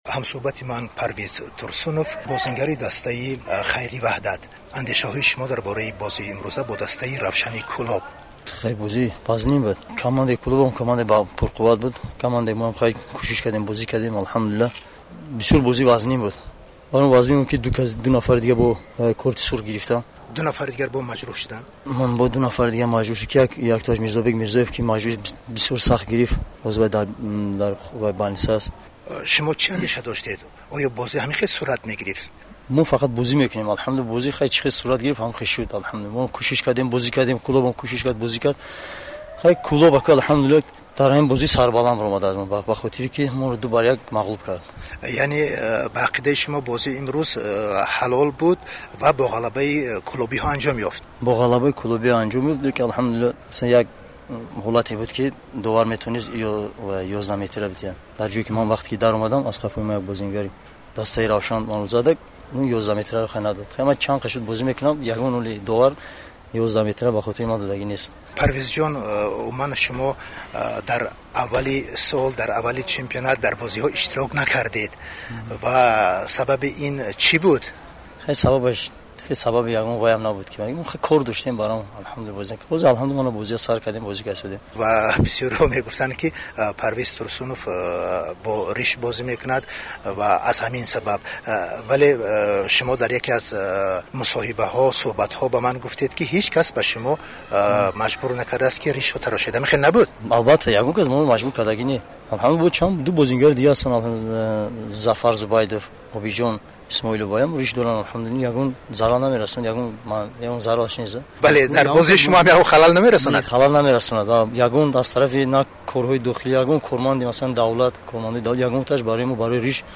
Гуфтугӯи ихтисосӣ